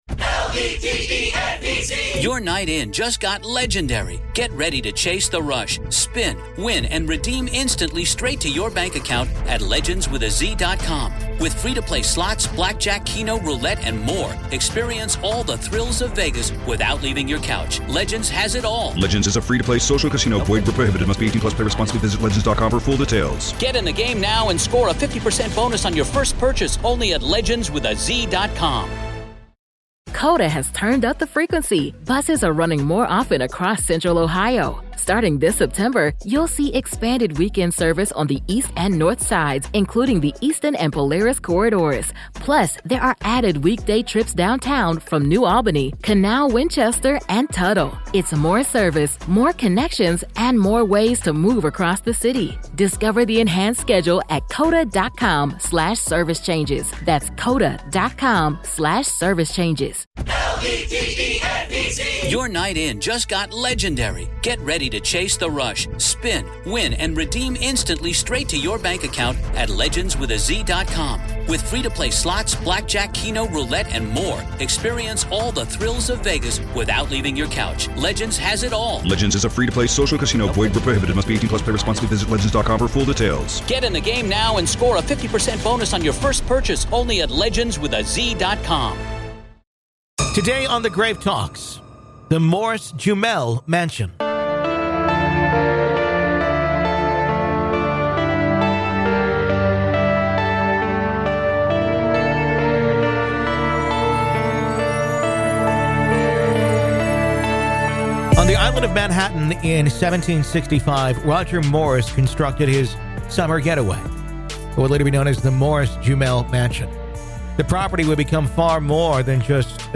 Today we talk with Paranormal Investigator